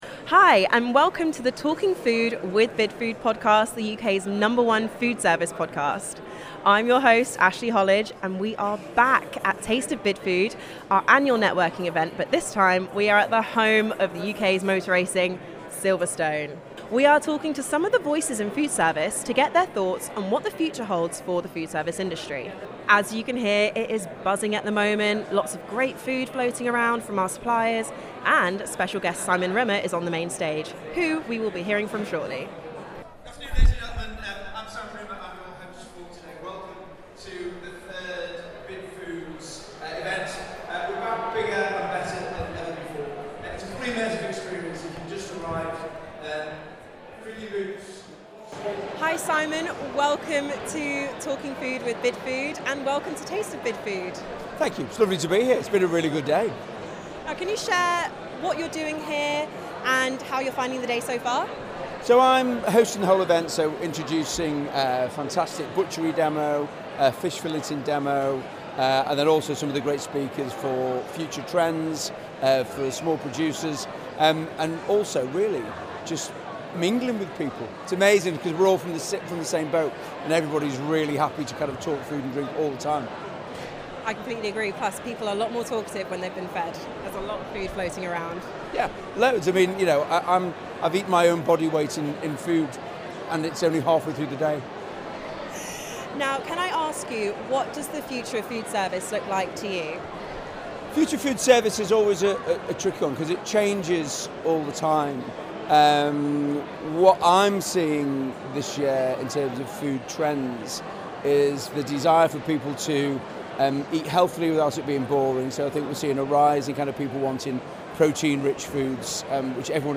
live from the Taste of Bidfood event